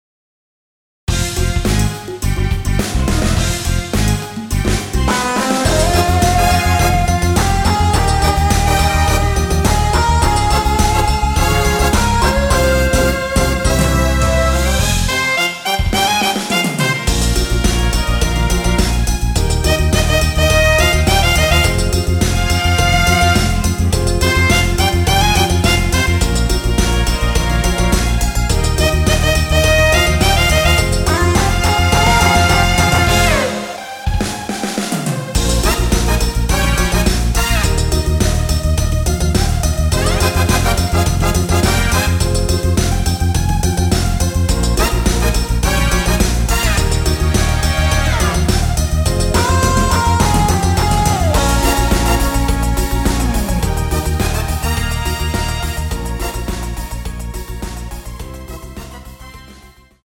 원키에서(+5)올린 멜로디 포함된 MR입니다.
Fm
앞부분30초, 뒷부분30초씩 편집해서 올려 드리고 있습니다.
중간에 음이 끈어지고 다시 나오는 이유는